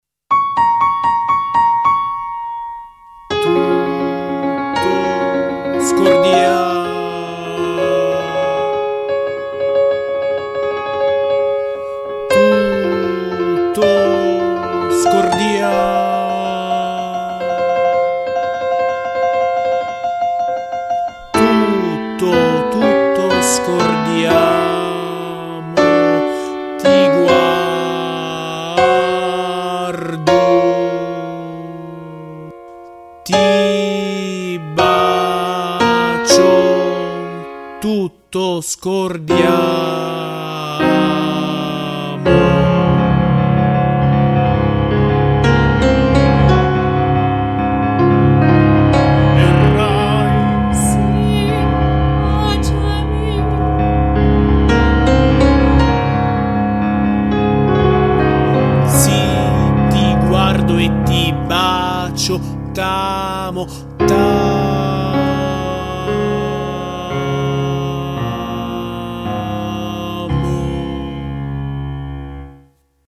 Don Din Don – Base musicale